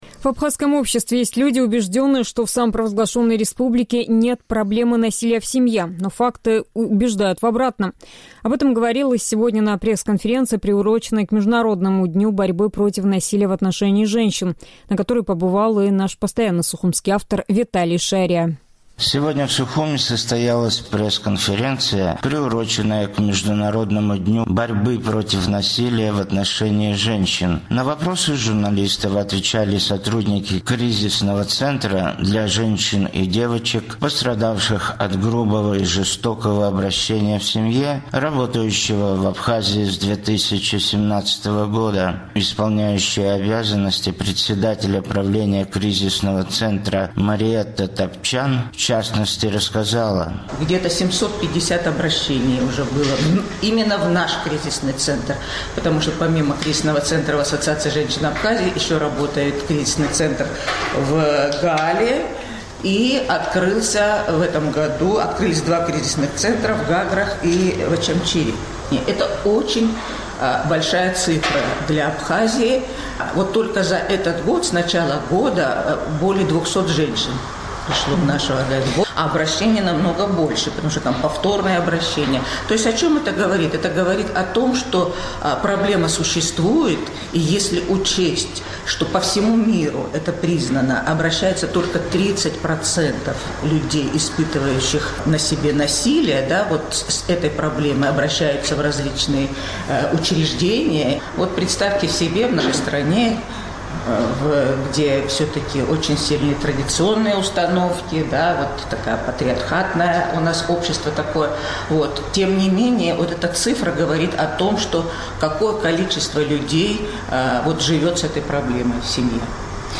Сегодня в Сухуме состоялась пресс-конференция, приуроченная к Международному дню борьбы против насилия в отношении женщин. На вопросы журналистов отвечали сотрудники Кризисного центра для женщин и девочек, пострадавших от грубого и жестокого обращения в семье, работающего в Абхазии с 2017 года.